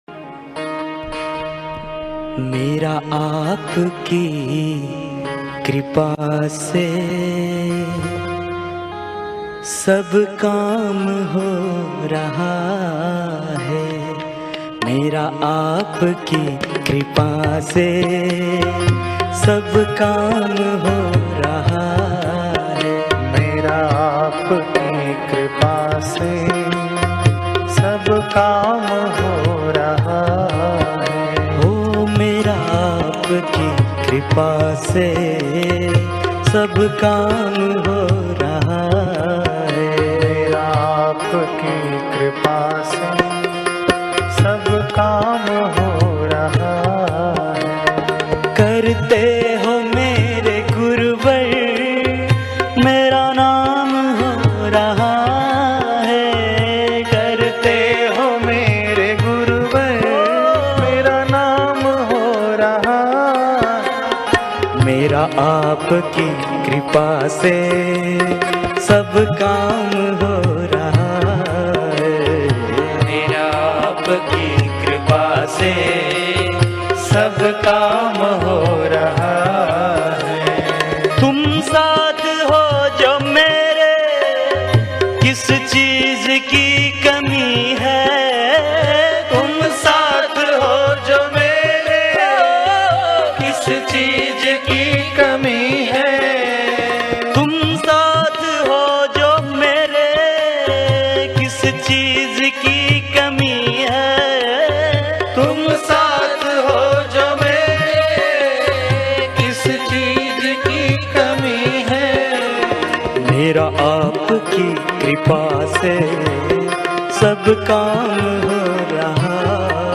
Audio Bhajan MP3s